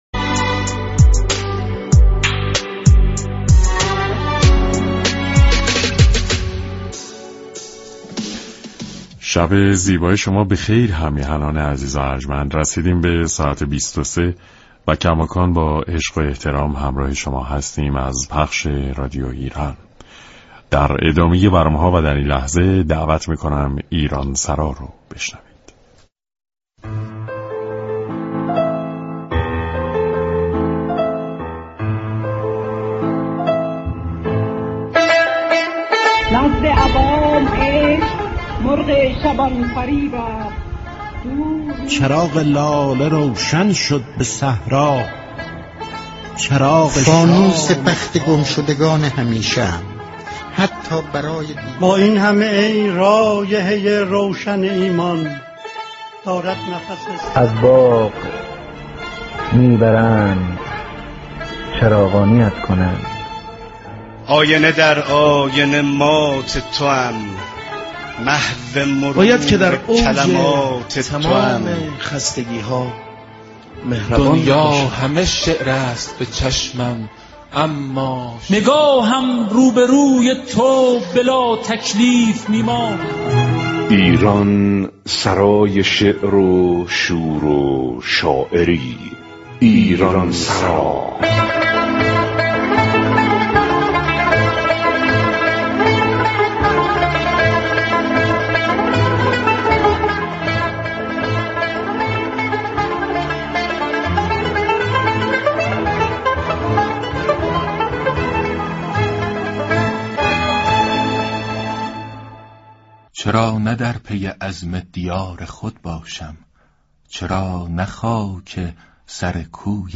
در این برنامه که جمعه شب حوالی ساعت ۲۳ از رادیو ایران پخش شد، شاعران استان آذربایجان شرقی مهمان بودند و درباره زیست شاعرانه و تجربه‌های شخصی خود سخن گفتند و به شعرخوانی پرداختند.